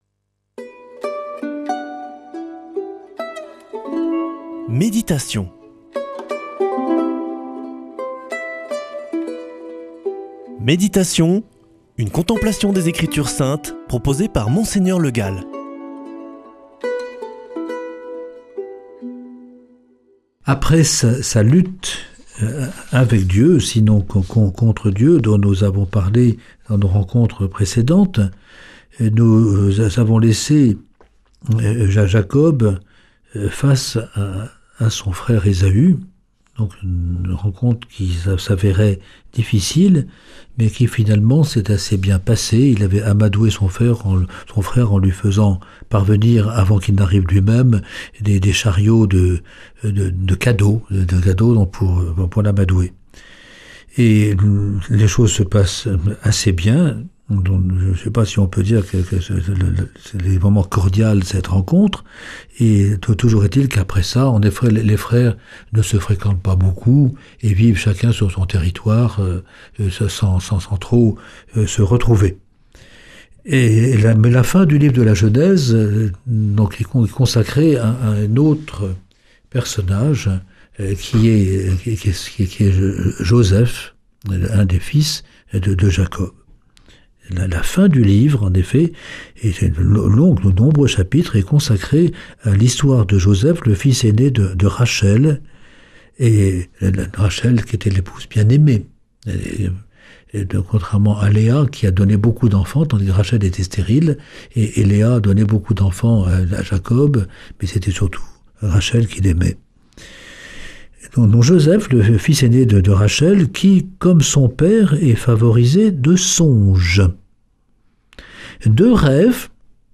Méditation avec Mgr Le Gall
Présentateur